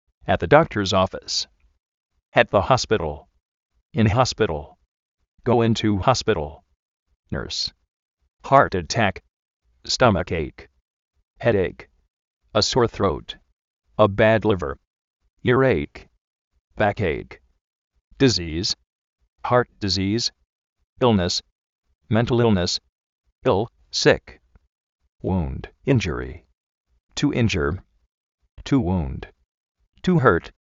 at de dóktors ófis
at de jóspital
nérs
járt aták